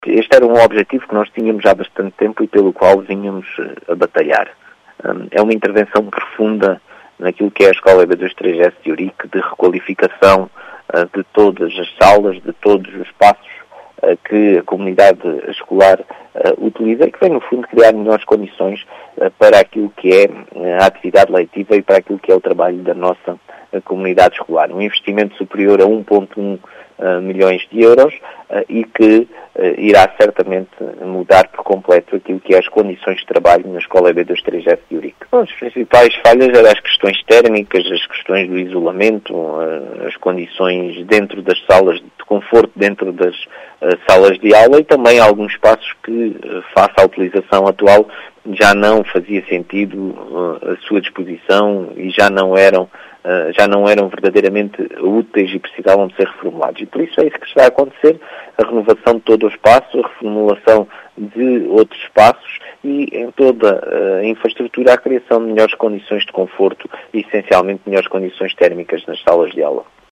Marcelo Guerreiro, presidente da Câmara Municipal de Ourique, fala numa “intervenção profunda”, que vem trazer “melhores condições para aquele estabelecimento de ensino.